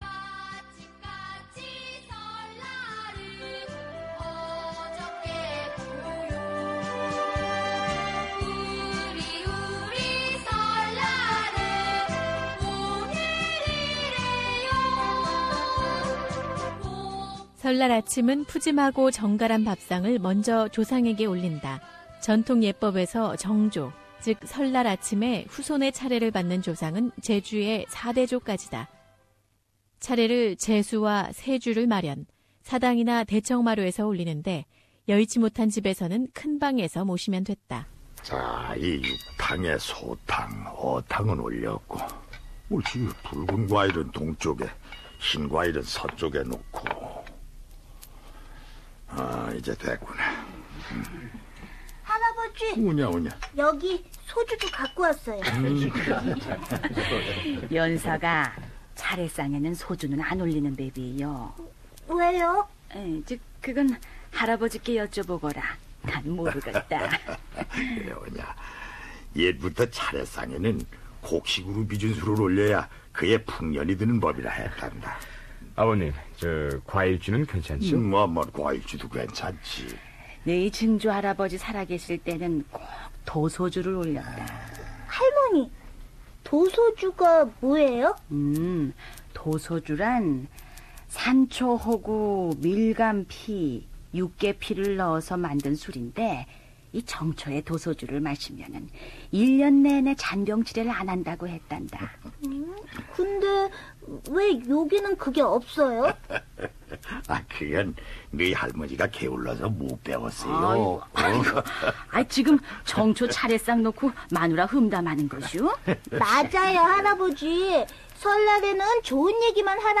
[설특집 다큐] 음력설의 의미와 풍습